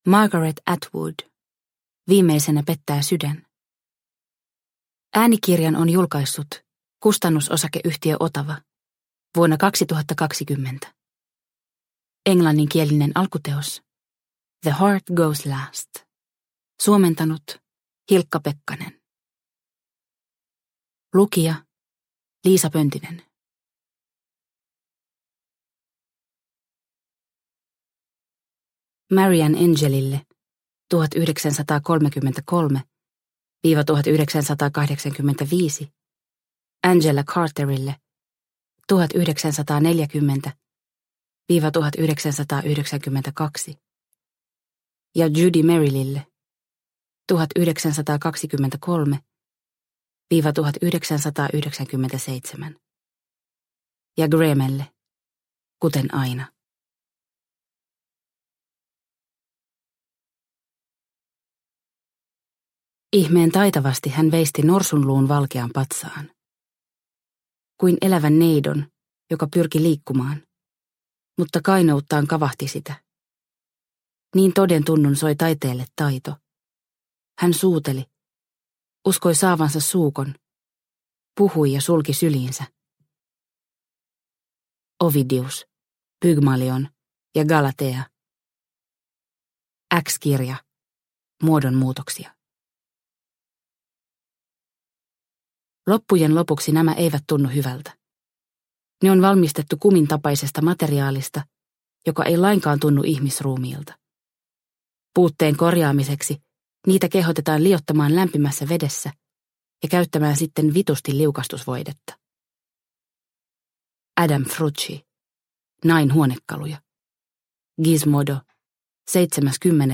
Viimeisenä pettää sydän – Ljudbok – Laddas ner